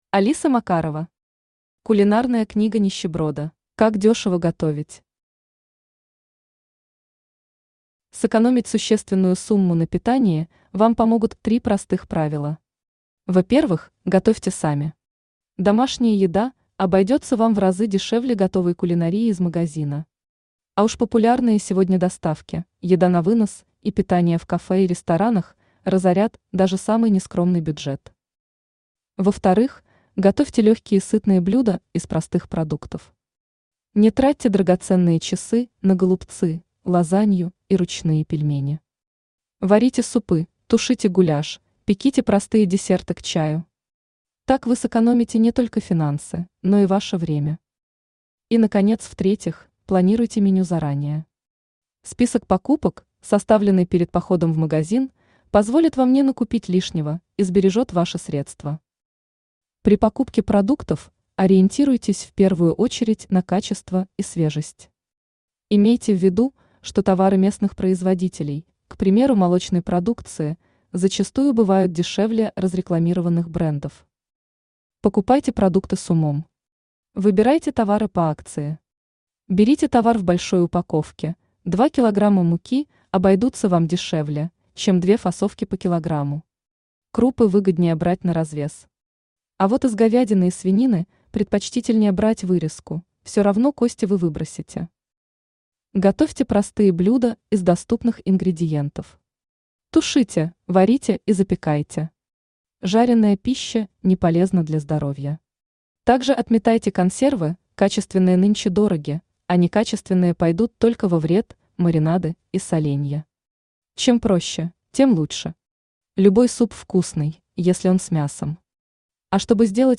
Аудиокнига Кулинарная книга нищеброда | Библиотека аудиокниг
Aудиокнига Кулинарная книга нищеброда Автор Алиса Макарова Читает аудиокнигу Авточтец ЛитРес.